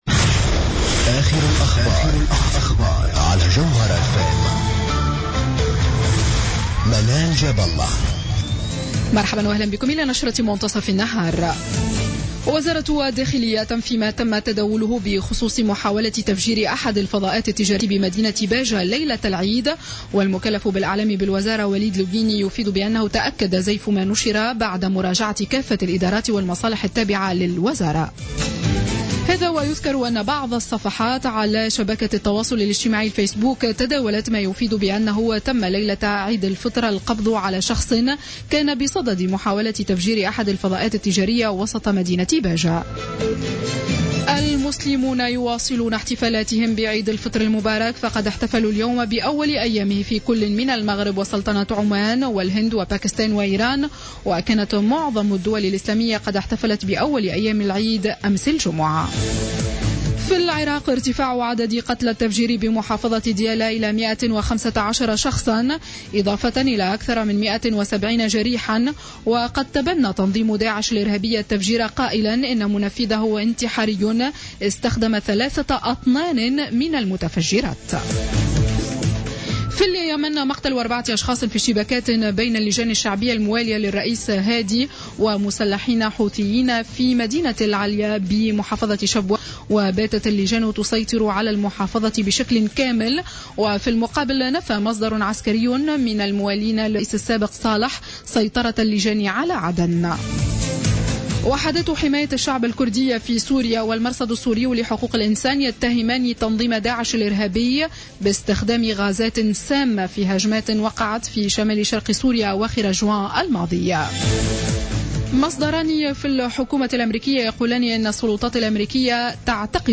نشرة أخبار منتصف النهار ليوم السبت 18 جويلية 2015